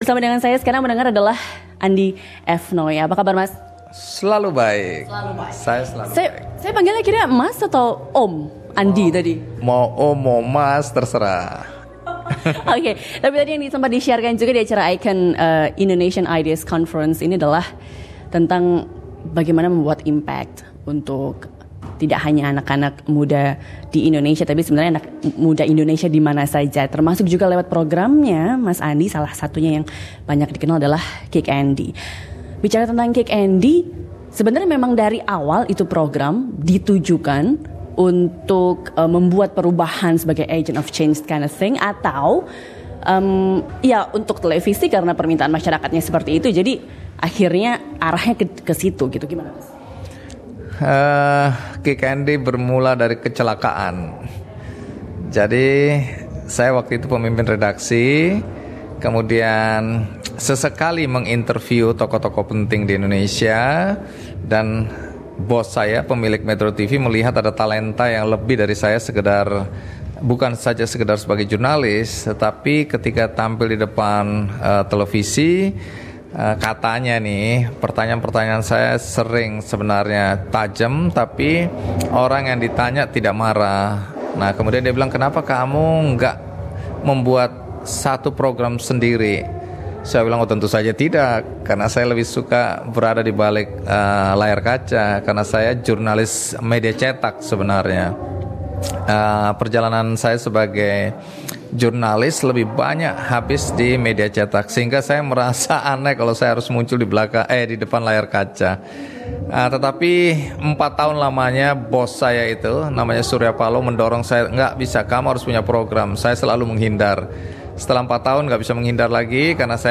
Acara Indonesian Ideas Conference ICON 2017 yang diselenggarakan oleh PPIA UNSW menghadirkan salah satu tokoh televisi terkemuka Indonesia, Andy F. Noya, untuk berbagi bagaimana hasil kerjanya dapat mengubah hidup orang lain.